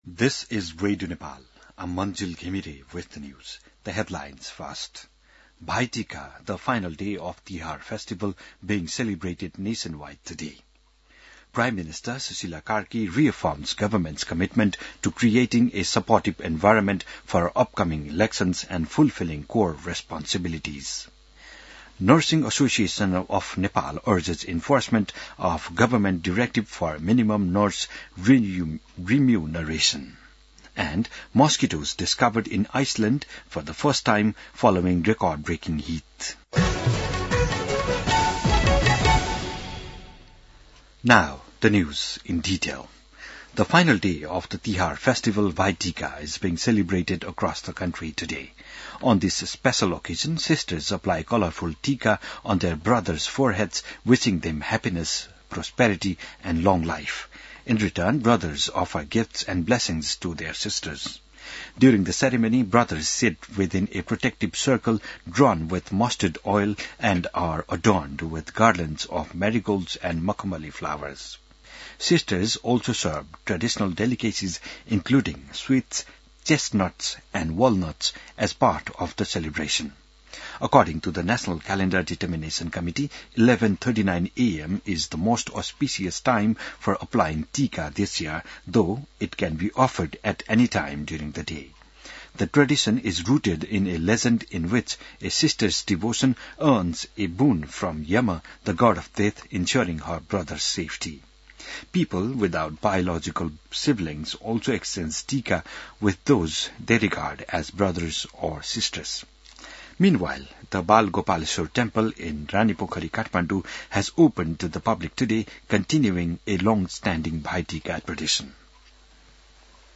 बिहान ८ बजेको अङ्ग्रेजी समाचार : ६ कार्तिक , २०८२